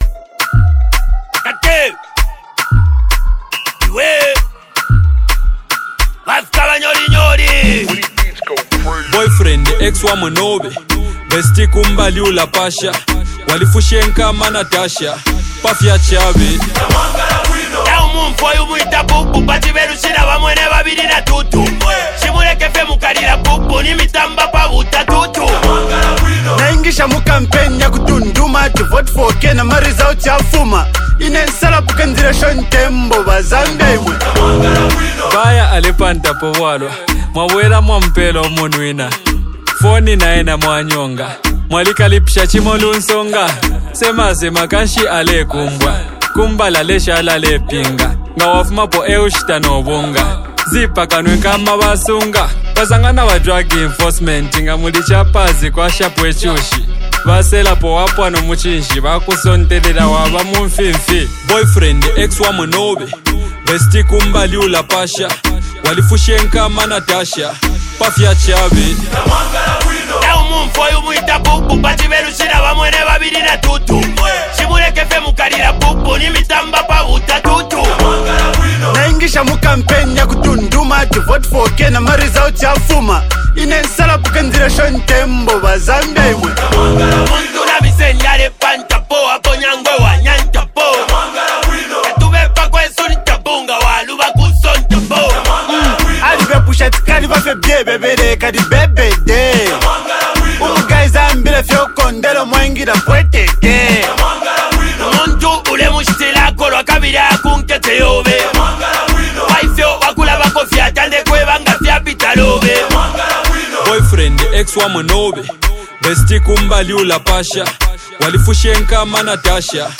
the Zambian duo